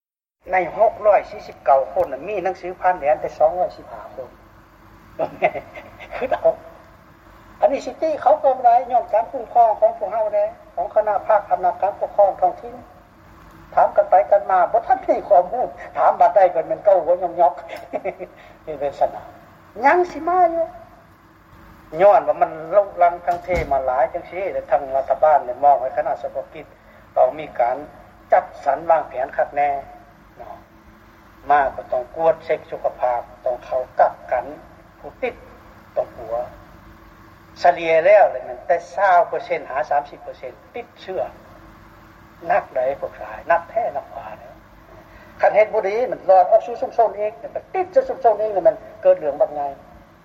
ນາຍົກລັດຖະມົນຕີລາວ ທ່ານພັນຄຳ ພິລາວັນ ກ່າວກ່ຽວກັບແຮງງານລາວທີ່ກັບຄືນມາບ້ານຈາກໄທ